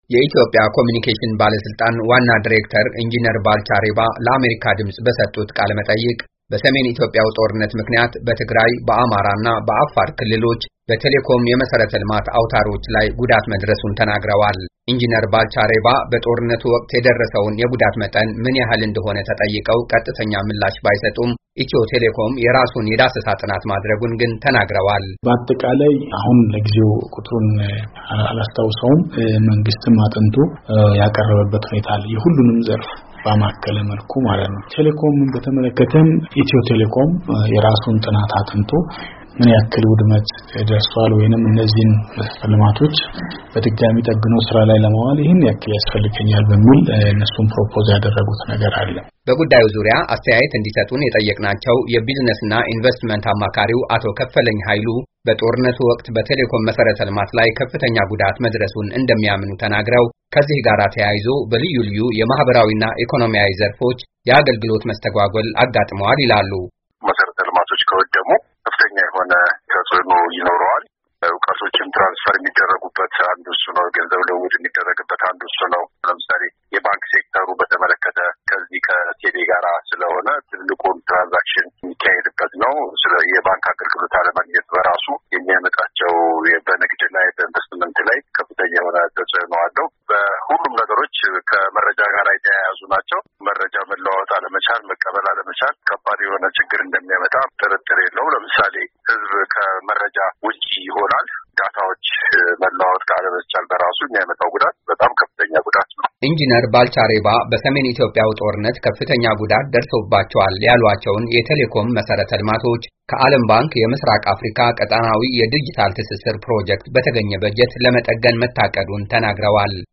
በሰሜን ኢትዮጵያው ጦርነት ጉዳት የደረሰባቸው የቴሌኮም መሰረተ ልማቶችን ለመጠገን ማቀዱን የኢትዮጵያ ኮምዩኒኬሽንስ ባለሥልጣን አስታወቀ። የባለሥልጣን መስሪያ ቤቱ ዋና ዲሬክተር ባልቻ ሬባ ለአሜሪካ ድምፅ በሰጡት ቃለመጠይቅ፣ ተቋማቸው በጦርነት የተጎዱ የቴሌኮም መሰረተ ልማቶችን መልሶ ለመገንባትና ሌሎችም ተያያዝ ፕሮጀክቶችን ለማከናወን፣ የ80 ሚሊዮን ዶላር በጀት መያዙን አመልክተዋል፡፡
በጉዳዩ ላይ ለአሜሪካ ድምፅ አስተያየት የሰጡ አንድ የንግድና ኢንቨስትመንት ባለሞያ ደግሞ፣ በቴሌኮም መሰረተልማቶች ላይ እየደረሰ ያለው ውድመት፣ በልዩ ልዩ ዘርፎች የአገልግሎት መጓደልን እያስከተለ መሆኑን ገልጸዋል። የመልሶ ግንባታ ሥራውም ከውጭ ምንዛሪ ጋራ የተያያዘ በመኾኑ ቀላል እንደማይኾን አብራርተዋል።